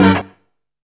CHICKEN STAB.wav